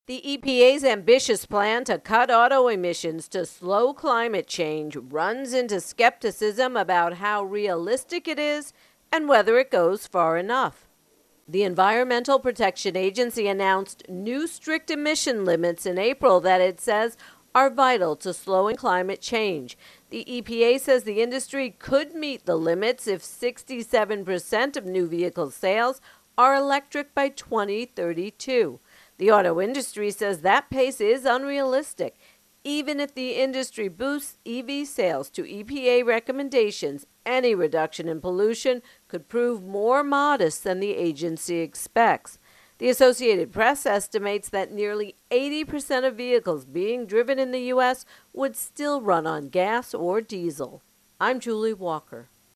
reports on EPA Auto Emissions